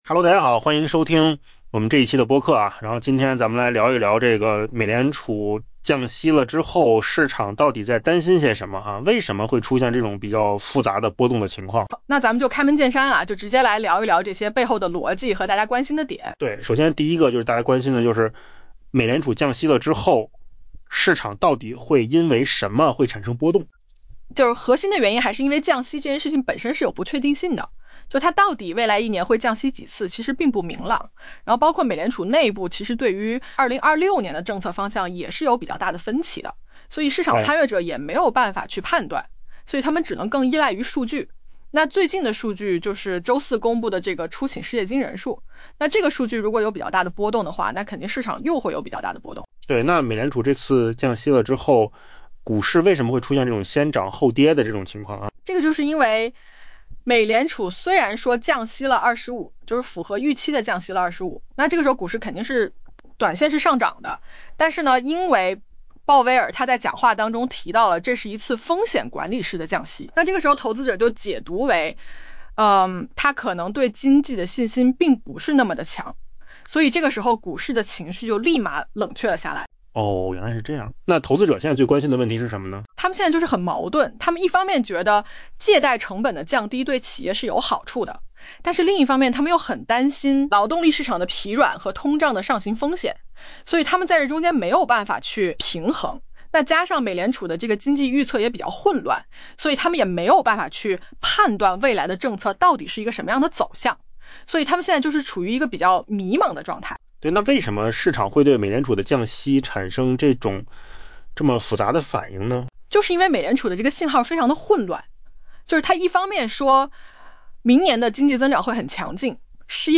AI 播客：换个方式听新闻 下载 mp3 音频由扣子空间生成 美联储降息后，市场通常会出现波动，但在周四凌晨，投资者似乎真的对央行释放的信号 「摸不着头脑」。